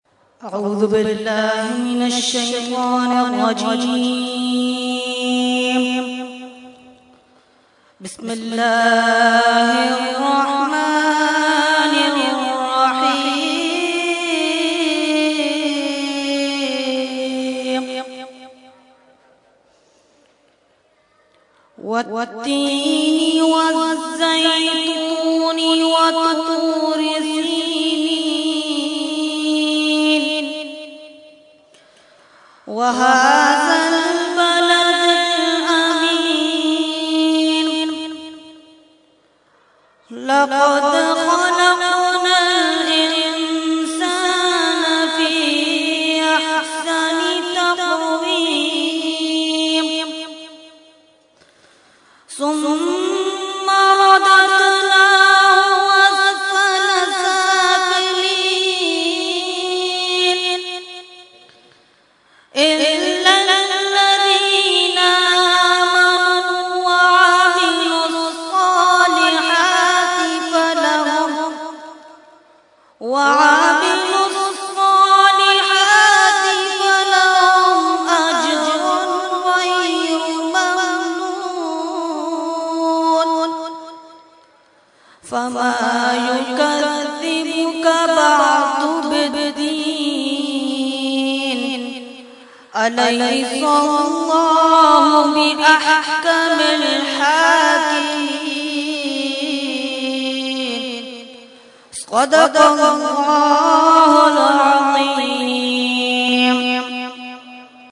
Category : Qirat | Language : ArabicEvent : Urs e Makhdoom e Samnani 2013